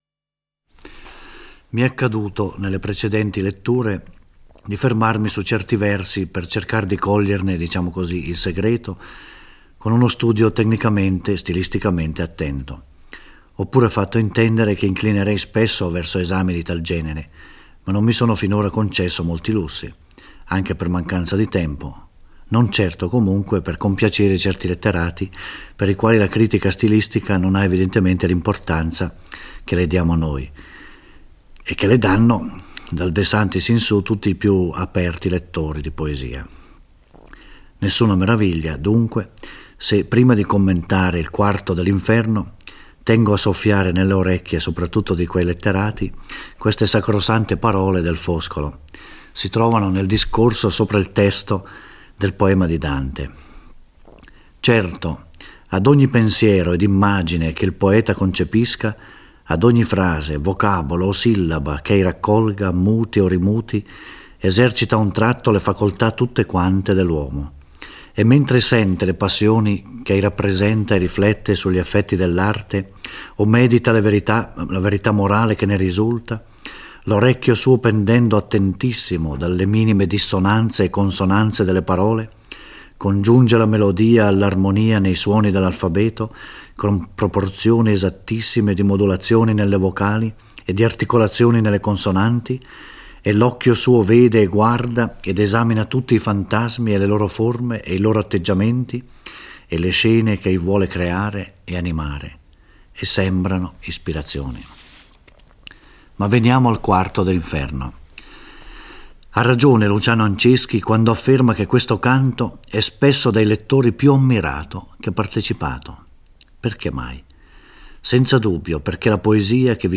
Giorgio Orelli legge e commenta il IV canto dell'Inferno, dove Dante si risveglia nel Limbo: il luogo in cui stanno i non battezzati privi di colpe. Virgilio lo conduce in uno spazio luminoso, dove c’è un castello circondato da sette cerchia di mura e da un fiumicello.